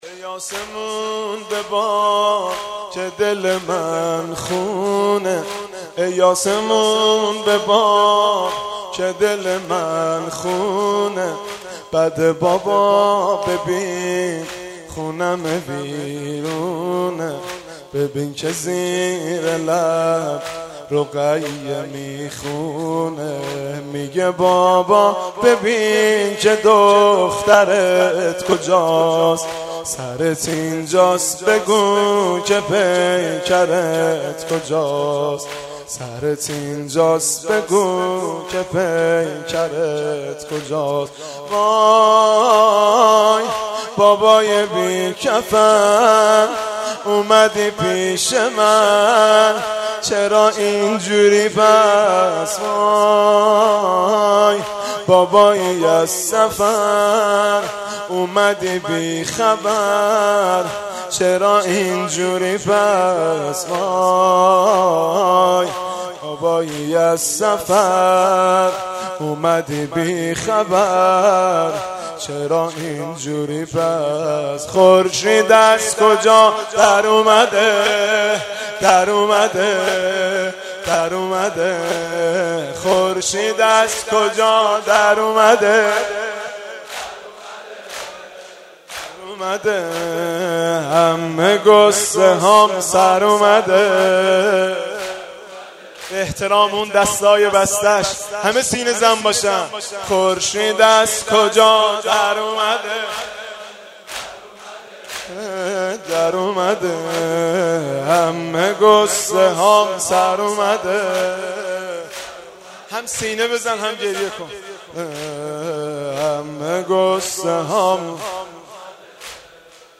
خیمه گاه - هیئت ثارالله(رهروان امام و شهدا) - بخش چهارم - زمینه (ای آسمون ببار که دل من خون)
شب سوم محرم 1394